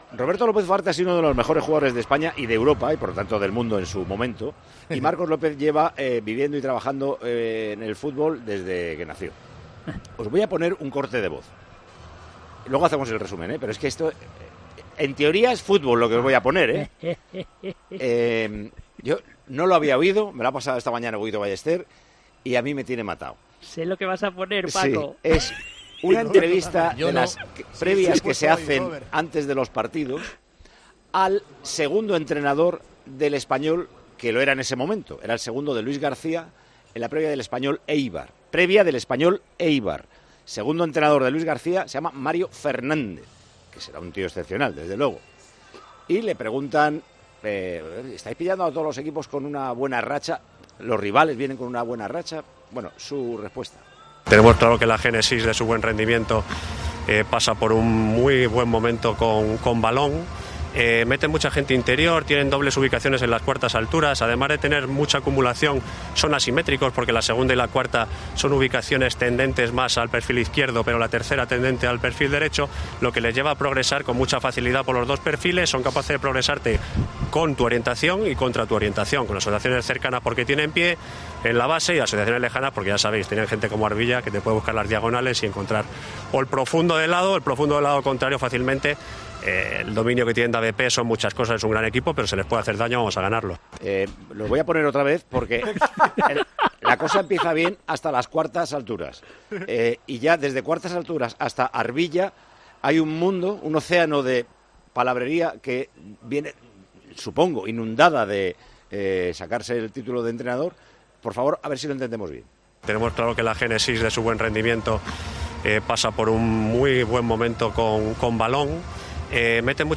La reacción de Paco González, López Ufarte y Marcos López en Tiempo de Juego
"Hay un oceáno de palabrería que viene, supongo, hinundada de sacarse el título de entrenador", decía Paco González que volvía a poner el sonido para intentar entender más el análisis.